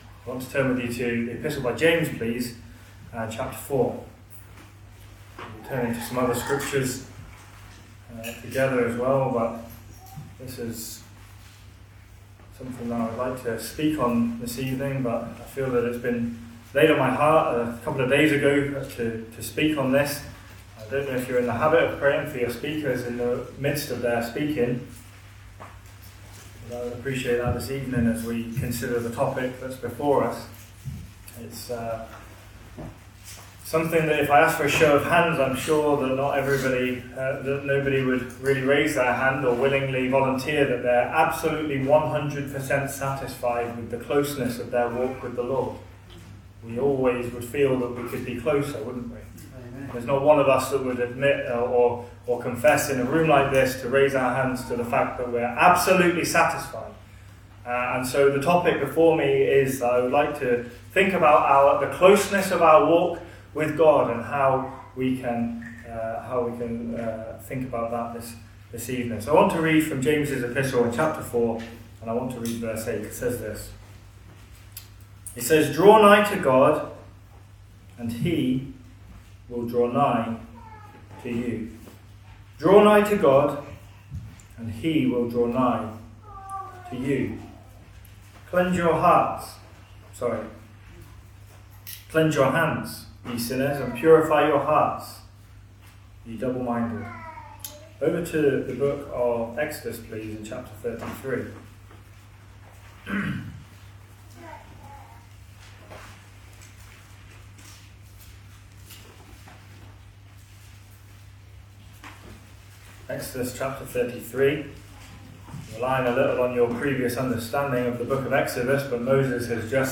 Message spoken on 16/10/2024